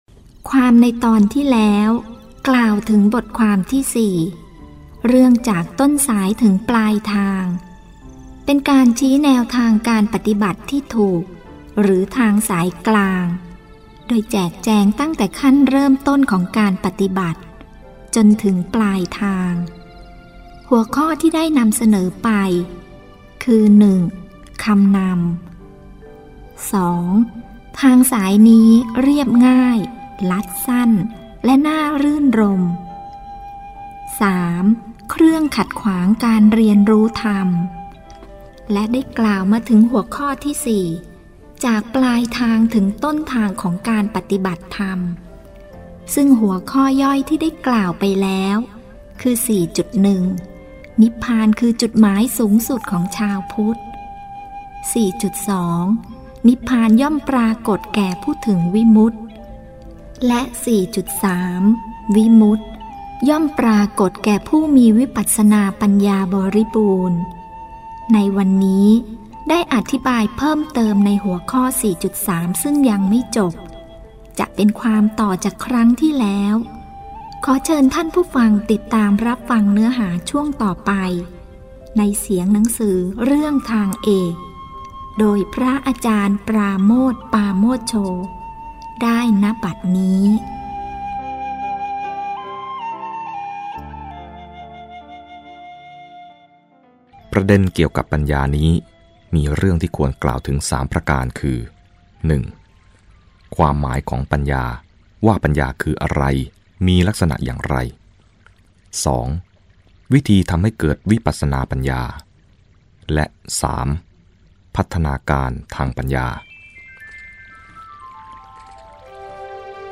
Main path to Dharma : Dharma Reading